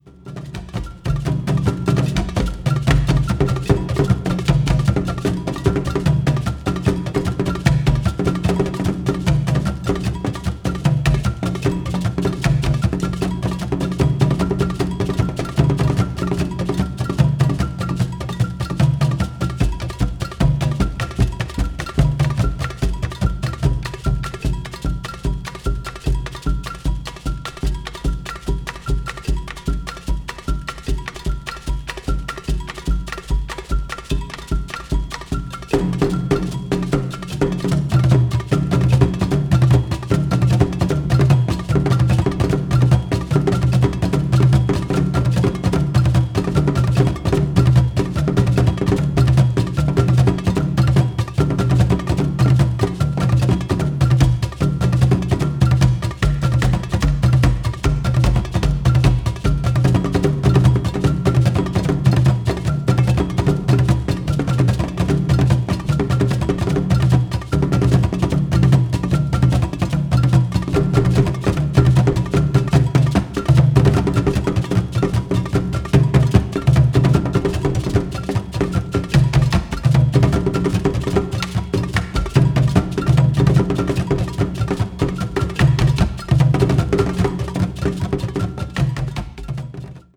Traditional African Music